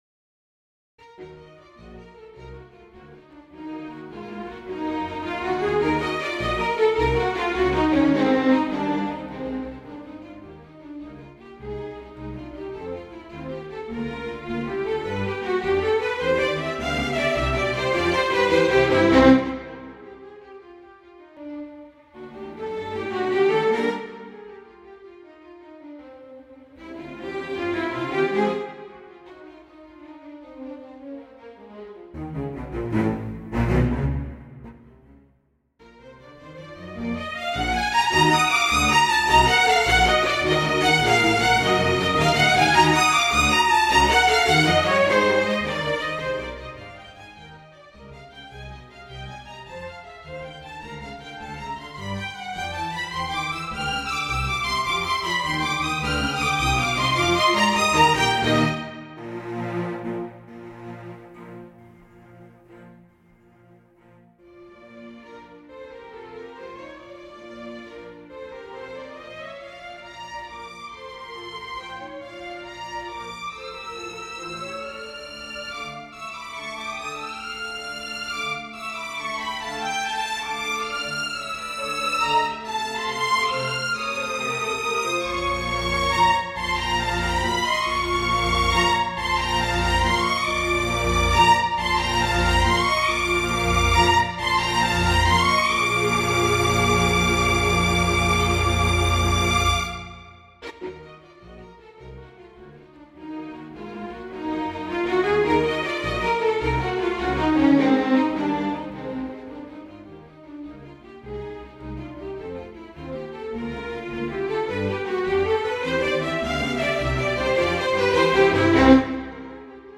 A Christmas Scherzo - Orchestral and Large Ensemble - Young Composers Music Forum
My inspiration was to write a fast Christmas piece as my pieces lately have all veered towards the same slow tempo. The form of the Scherzo is ternary and the overall form is Scherzo - Trio - Scherzo.